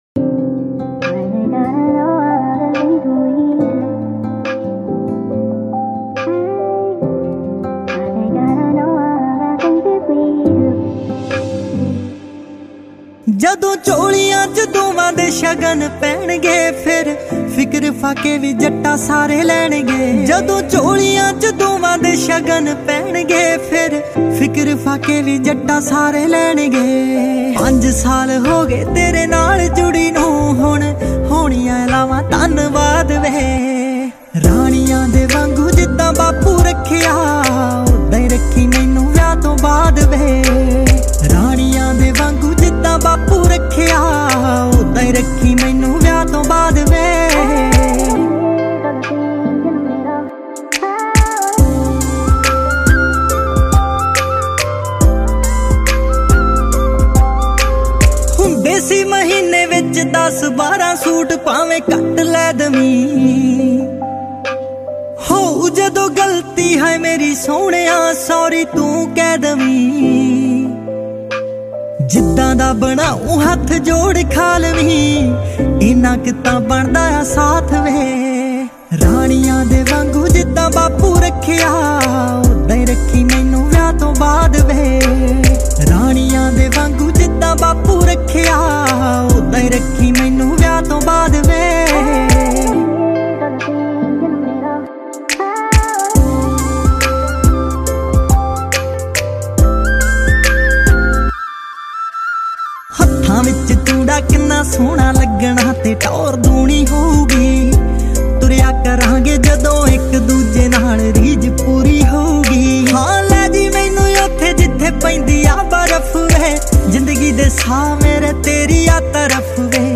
Punjabi Mp3 Songs